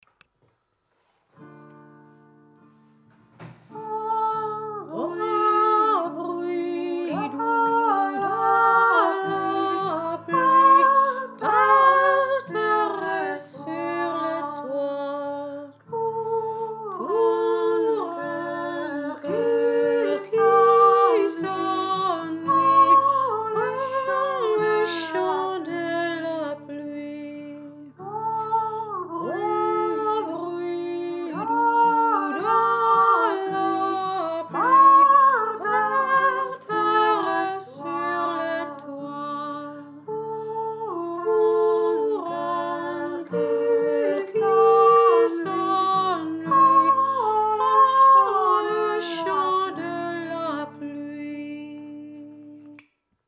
OH BRUIT DOUx als Kanon
Man kann es auch zu dritt singen, einnfach noch einen Schlag später einsetzen... probiert es!
oh-bruit-doux-kanon.mp3